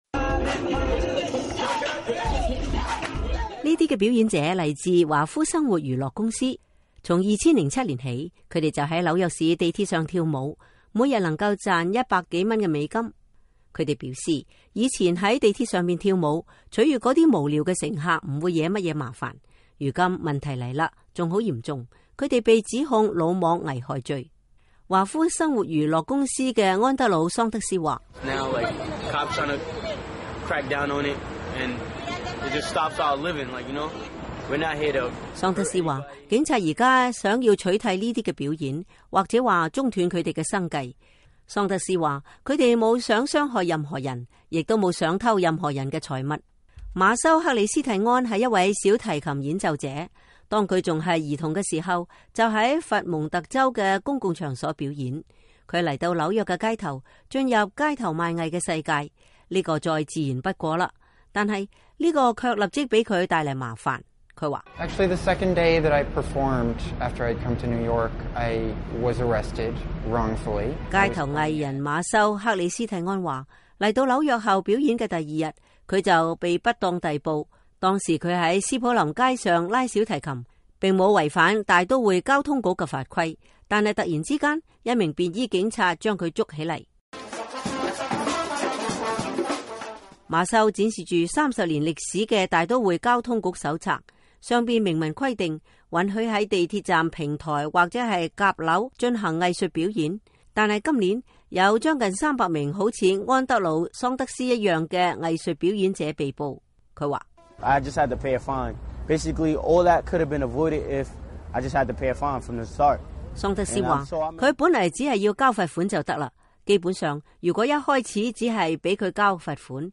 美國之音記者採訪了兩位街頭藝人。